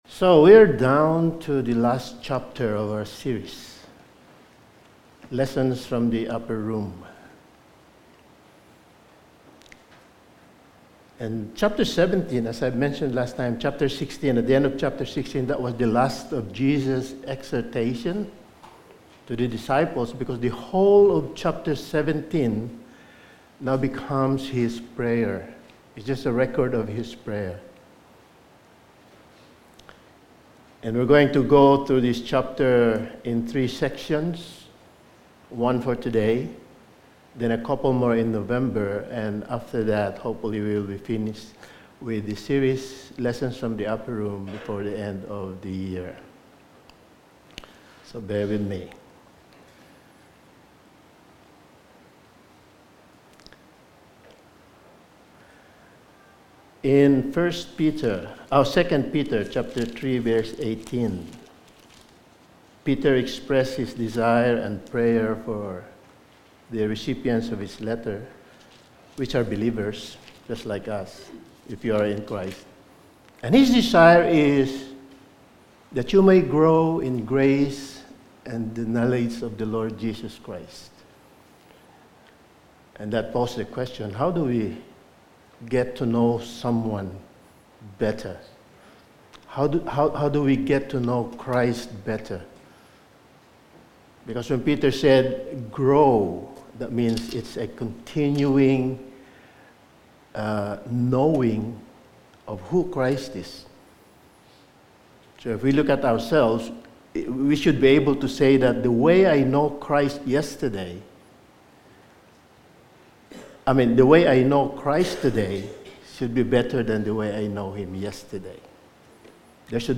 Lessons From the Upper Room Series – Sermon 12: The Heart of Christ Exposed
Passage: John 17:1-5 Service Type: Sunday Morning